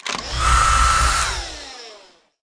Fan Launch Sound Effect
Download a high-quality fan launch sound effect.
fan-launch.mp3